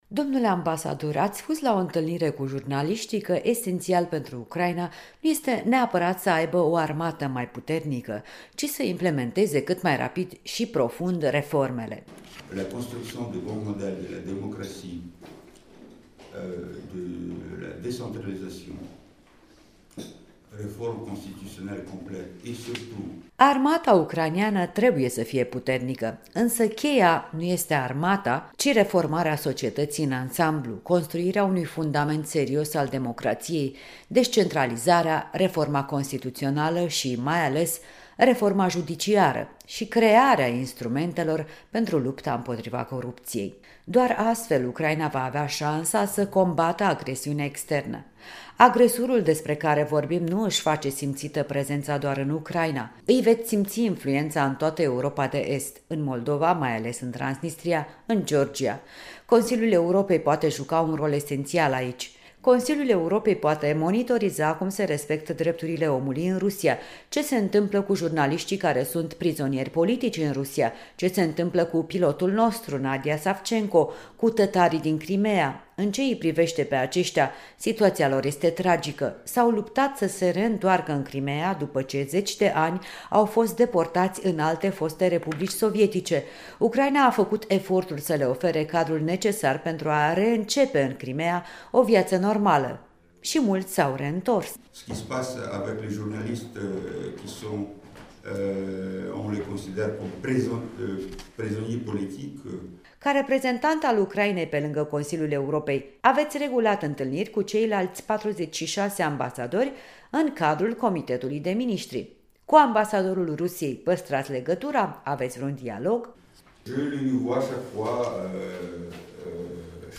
În direct de la Strasbourg cu ambasadorul ucrainean Mykola Tochytskyi